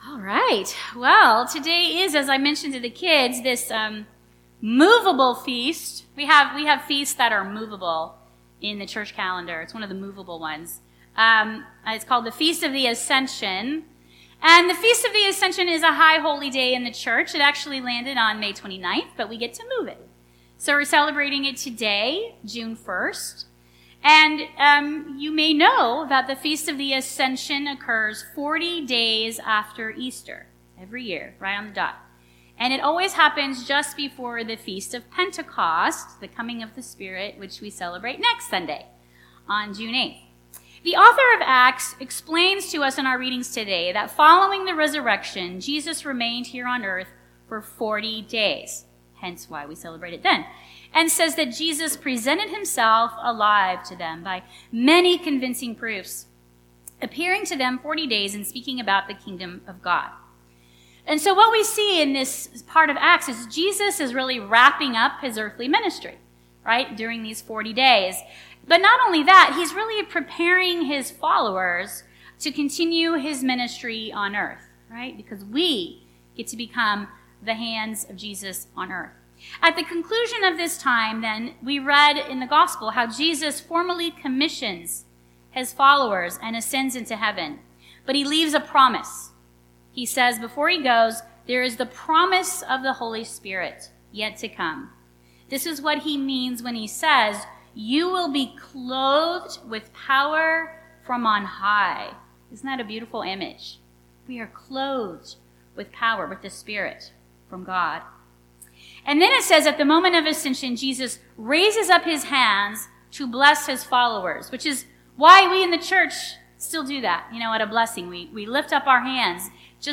Sunday's Sermon